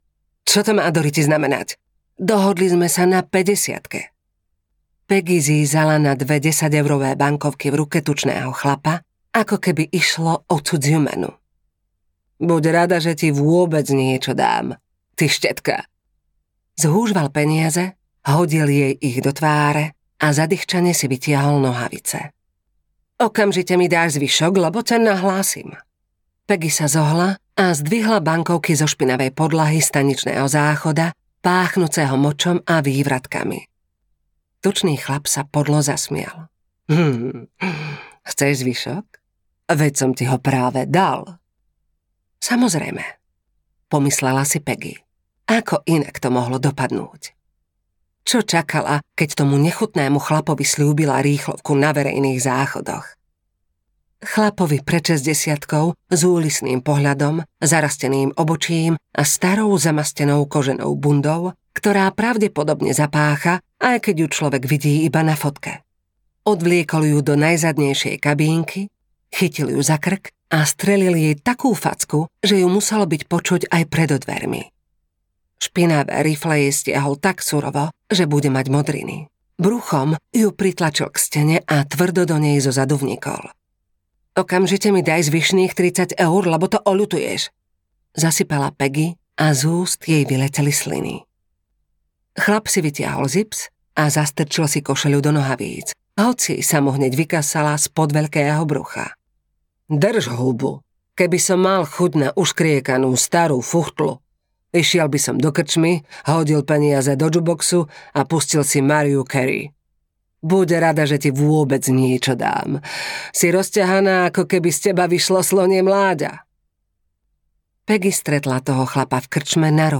Frekvencia smrti audiokniha
Ukázka z knihy